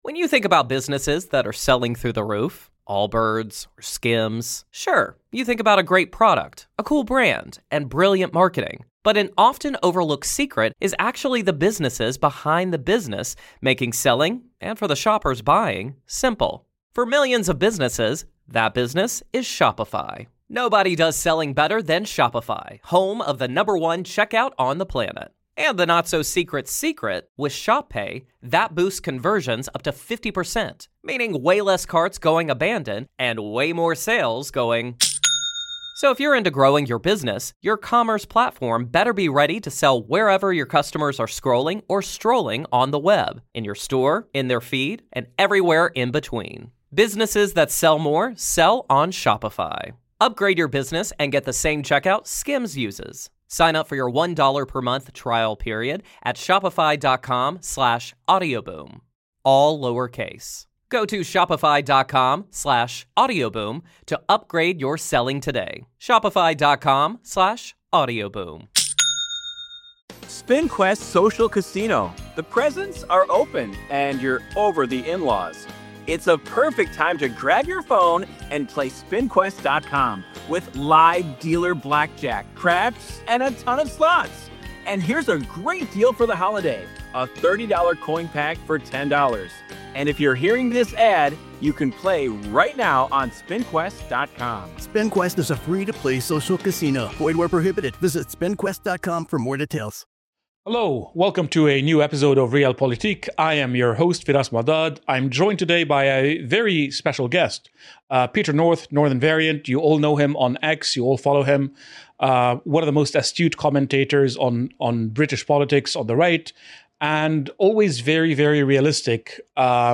an in-depth conversation on how we can fix Britain what’s gone wrong, what needs to change, and what the future could look like if we get it right.